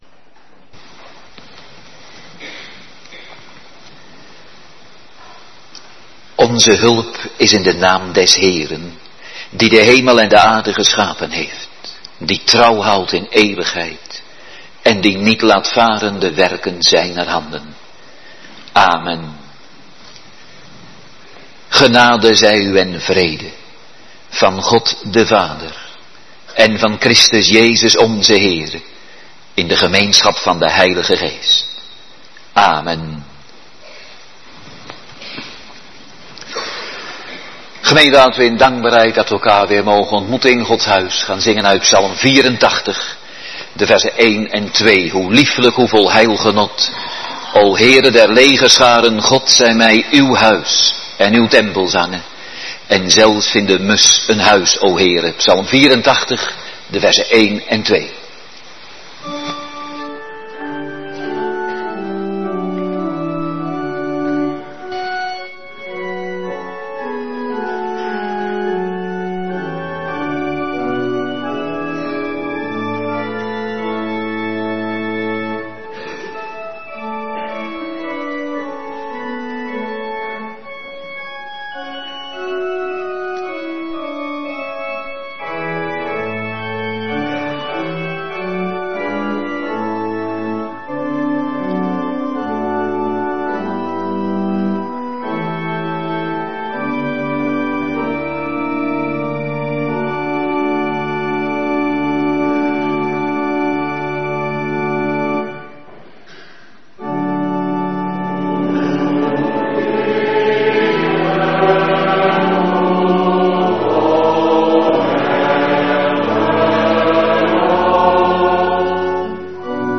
Morgendienst
Locatie: Hervormde Gemeente Waarder